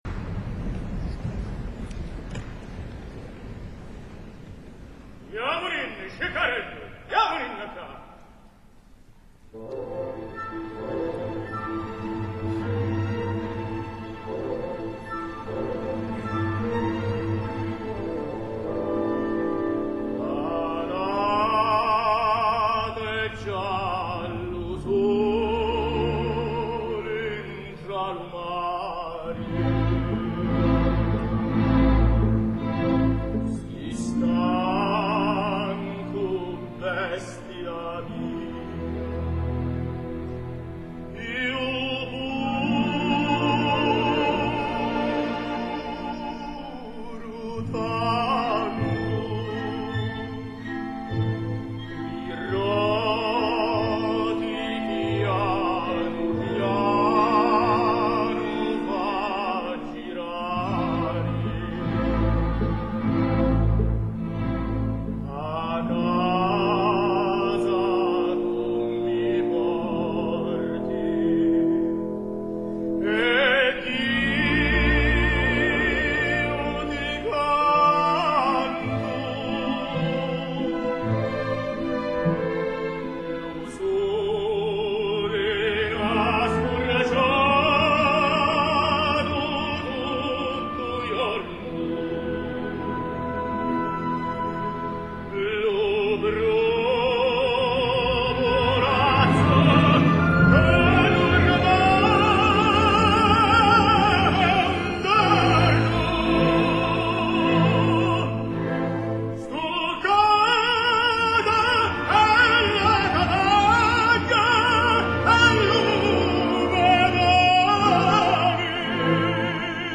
Roberto Alagna, tenor
Recital in Aarhus (Danemark)
• Sicilian song
Finalment us deixaré les dues sicilianes que varen tancar el concert.
És una música primitiva que surt del sentiment, del fons de l’ànima i són les peces que m’han electritzat més de tot el concert, especialment la darrera, cantada a capella amb un llunyà acompanyament de percussió.
2-sicilianes.mp3